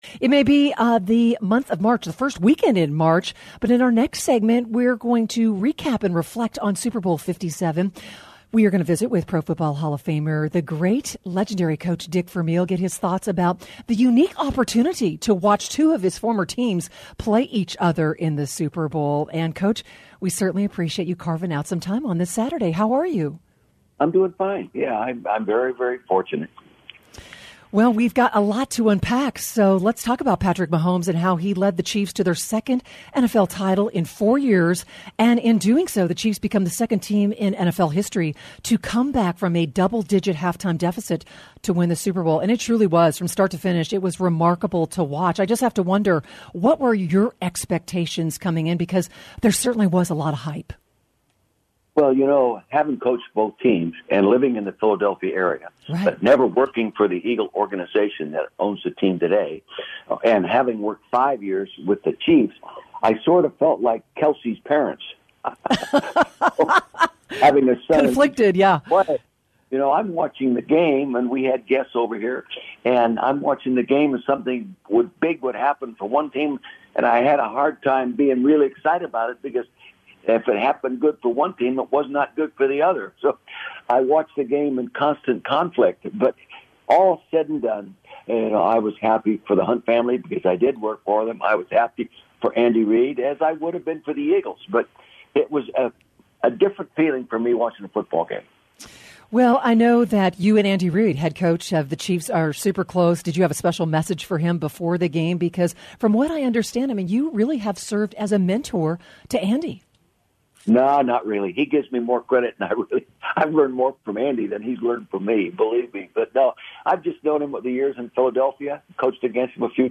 Legendary NFL coach Dick Vermeil joins The Big E Sports Show